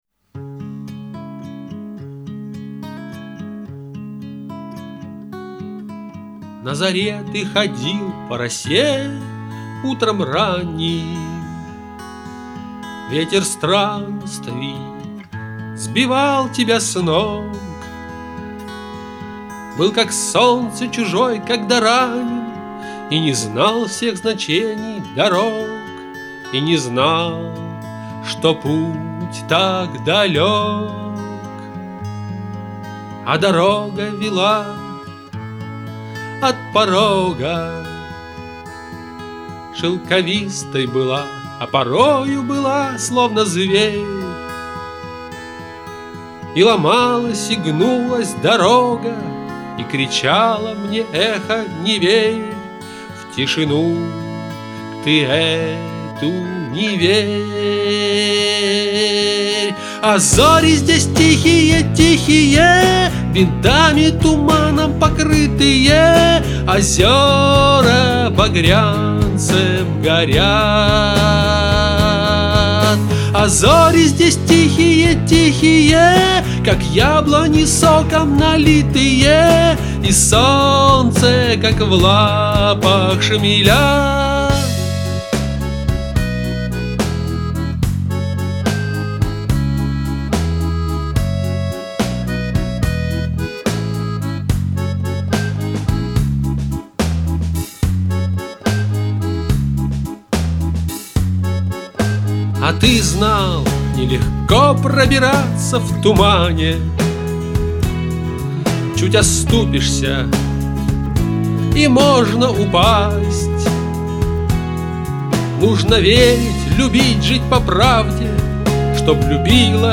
В куплетах декламация с пафосом, а нужно мягчеЙ и тоньЧЕЙ.